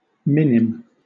Ääntäminen
Southern England: IPA : /ˈmɪ.nɪm/